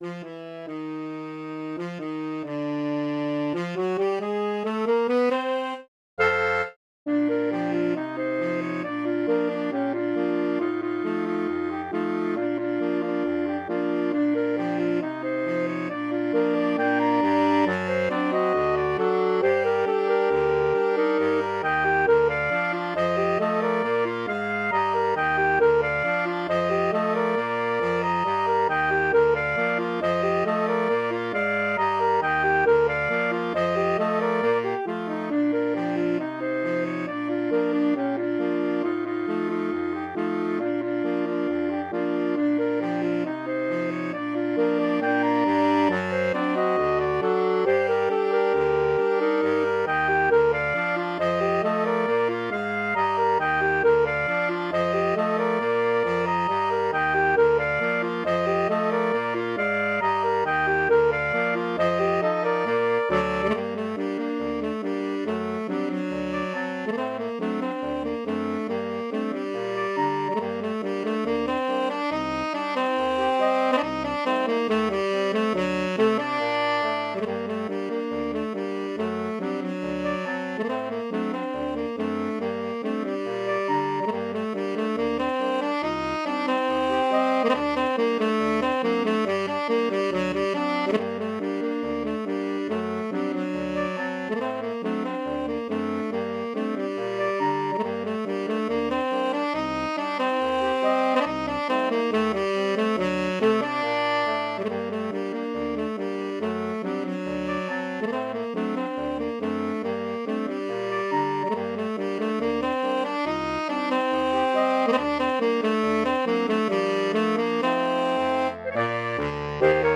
Arranged for saxophone quartet SATB or AATB.